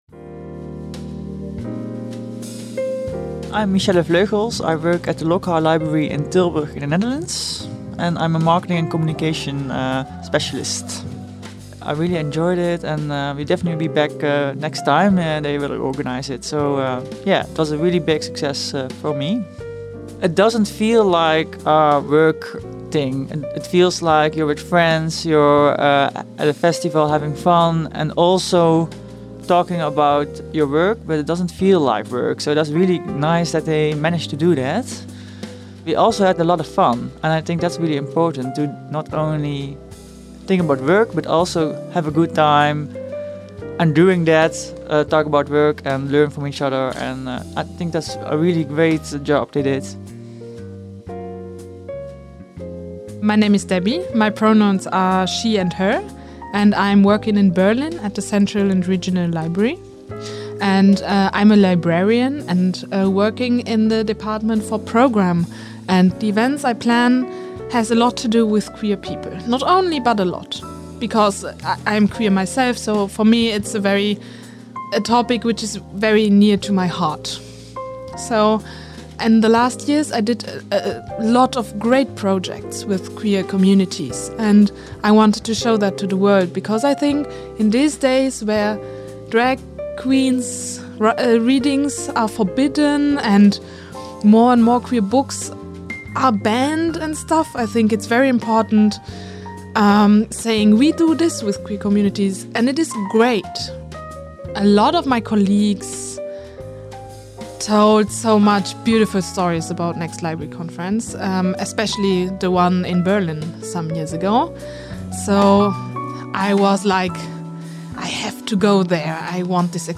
Many participants were interviewed about being at Next Library Festival in the Tiny Podcast Caravan.